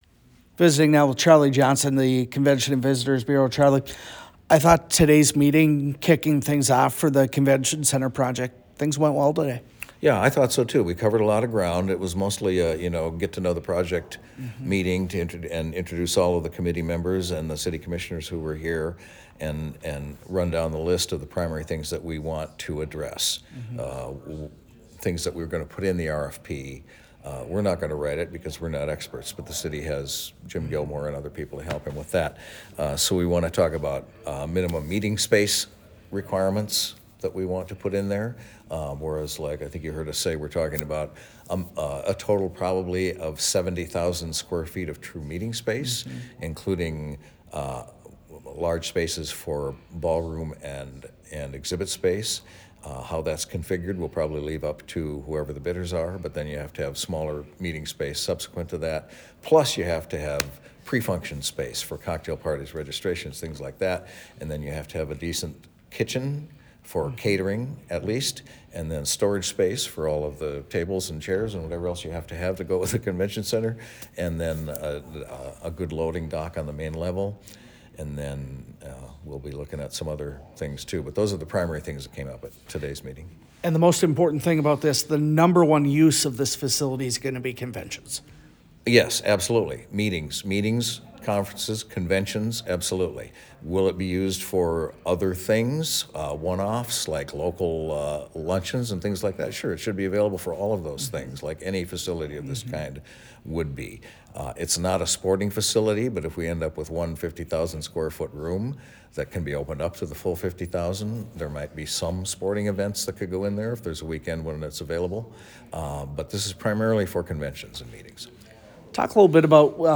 Interview with Fargo Mayor Dr. Tim Mahoney